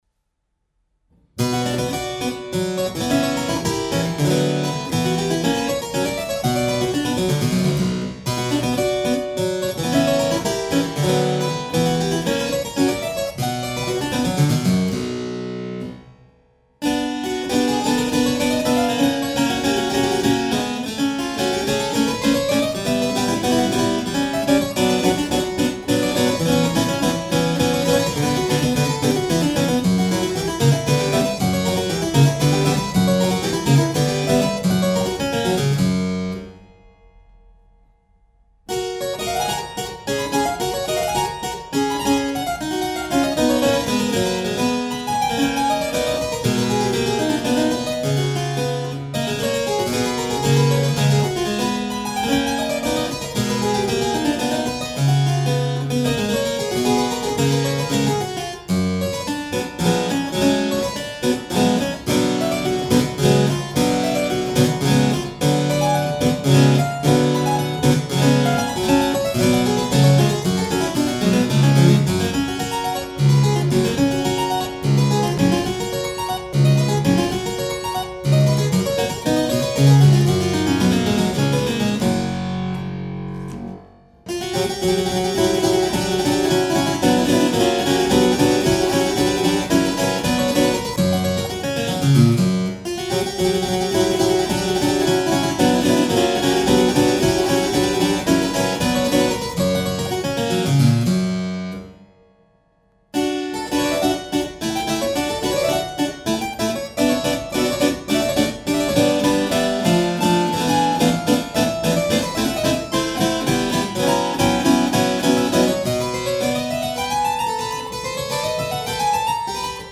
clavicembalo